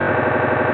retro_fail_sound_02.wav